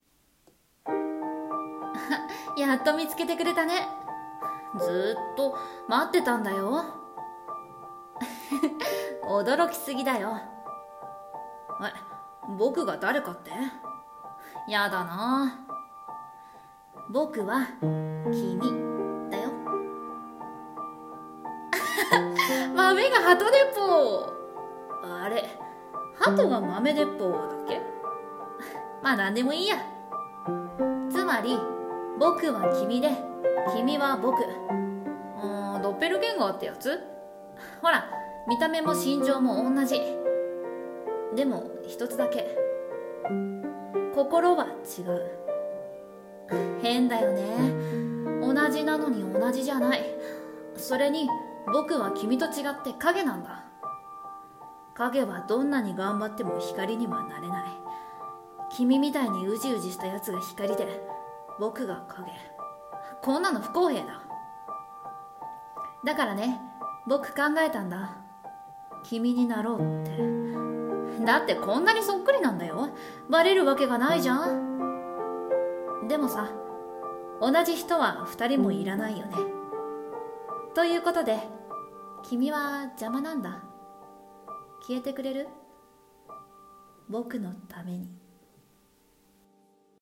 【声劇】